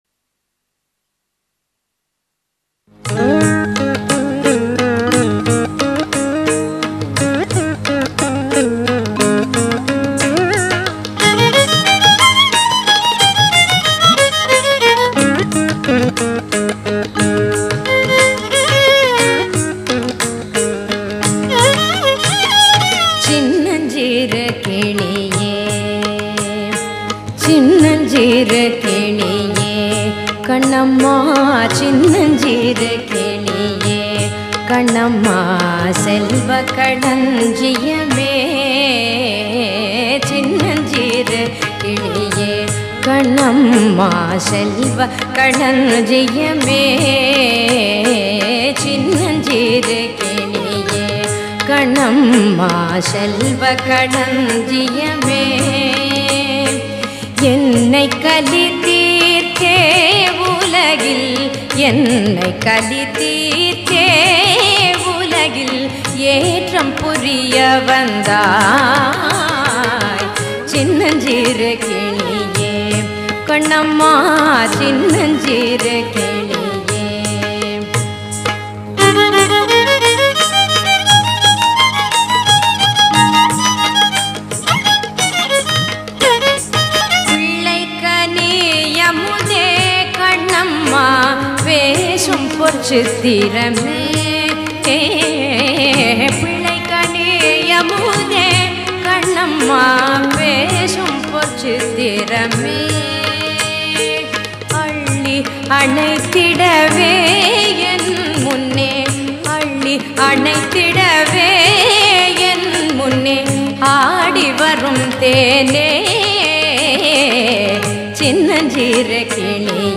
Chinnanchiru-Kiliye-Tamil-Classical-Songs-Bharatha-Samudayam.mp3